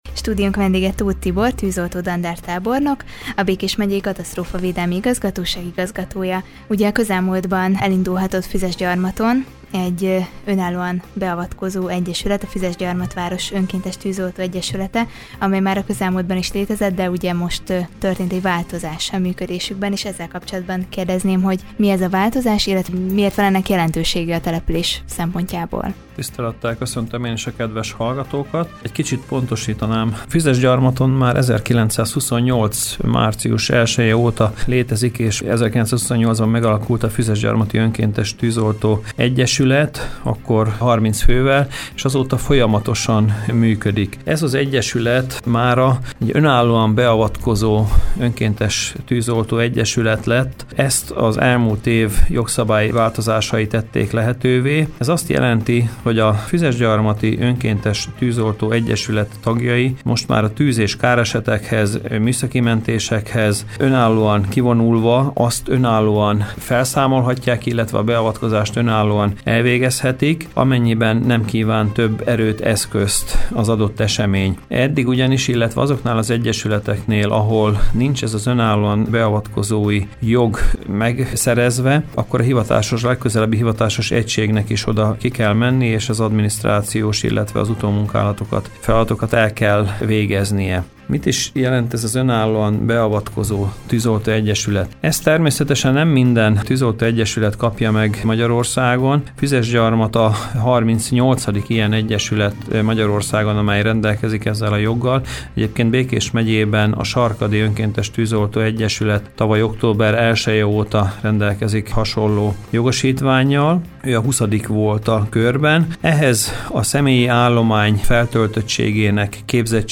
Tóth Tibor tűzoltó ezredes, a megyei Katasztrófavédelmi Igazgatóság igazgató volt a Körös Hírcentrum stúdiójának vendége. Vele beszélgettünk a szeptember 1-től önállóan is beavatkozható Füzesgyarmati önkéntes tűzoltó egyesületről.